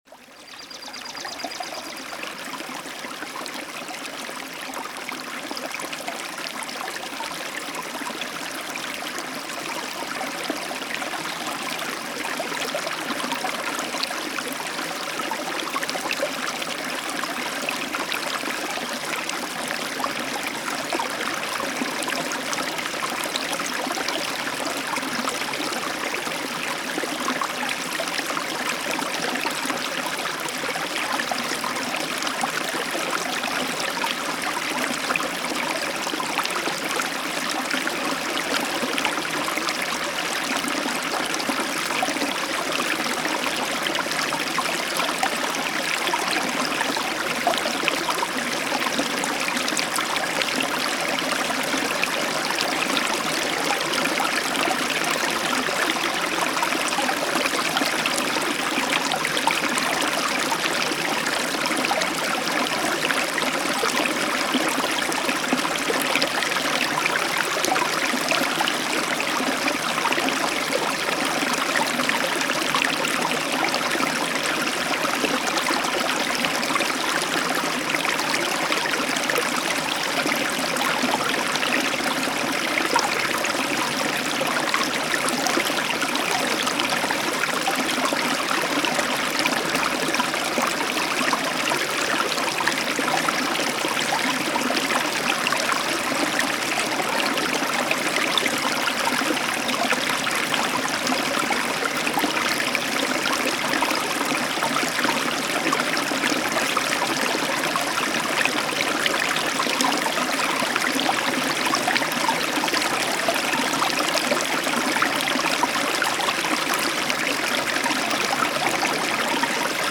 Лесной ручей
Даже не верится,что эти звуки искусственные.